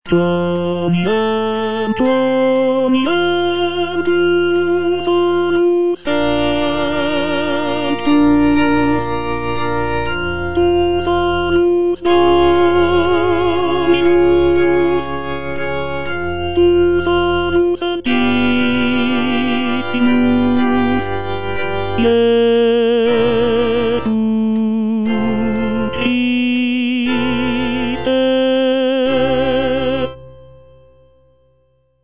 Tenor (Voice with metronome) Ads stop
choral work